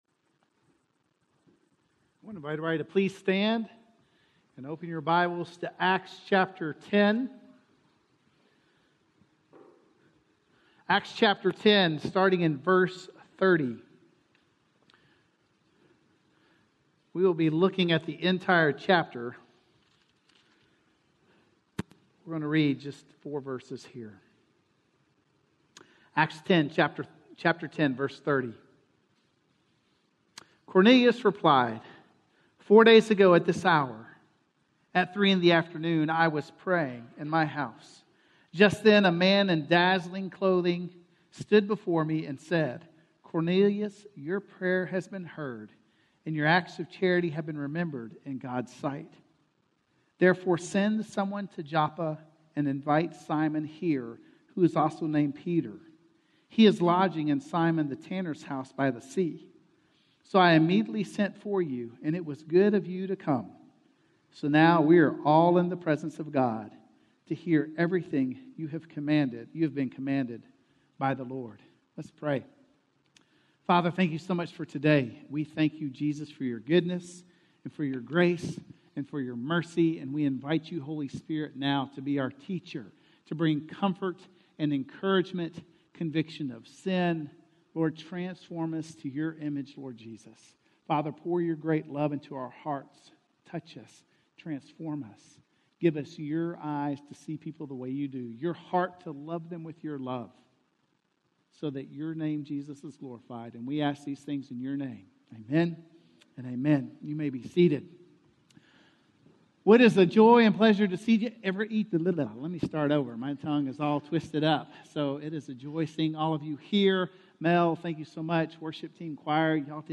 Two Sides of the Story - Sermon - Woodbine